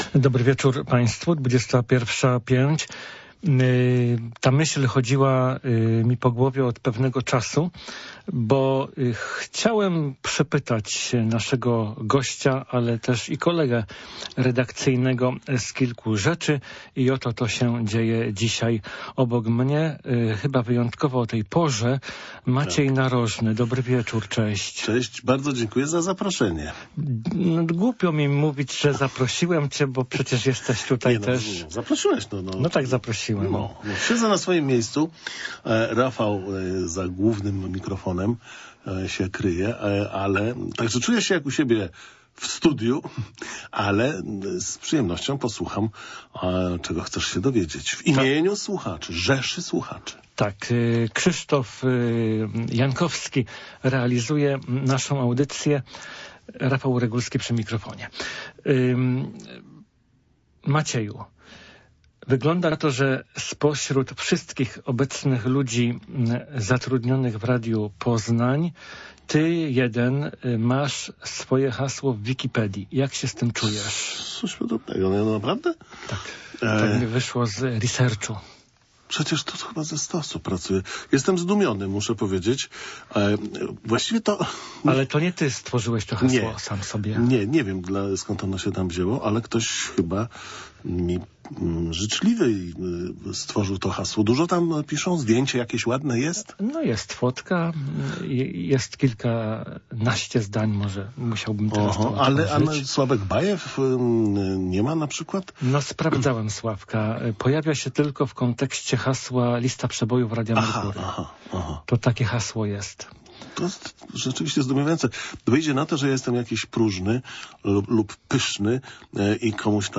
Gościem wieczornej audycji „Rozmowy po zachodzie” jest dziś